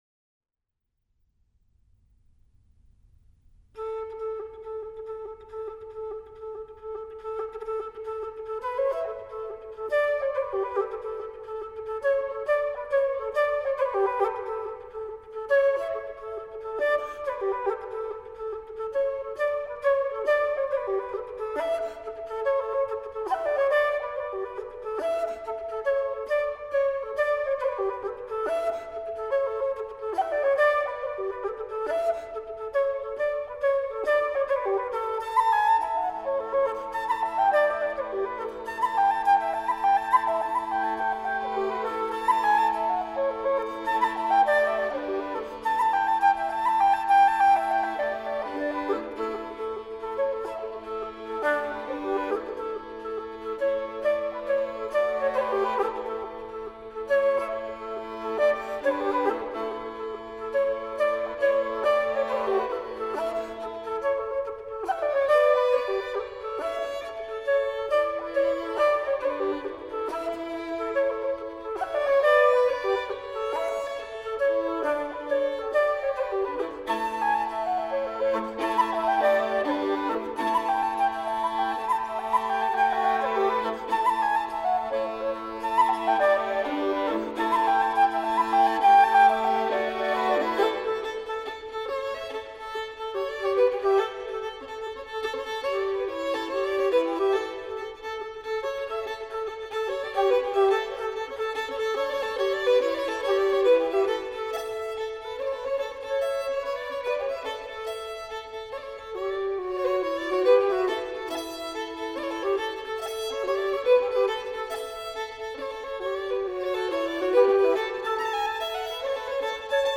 Wooden Flute
Fiddle
Accordian & Concertina
Guitar
Bass Tracks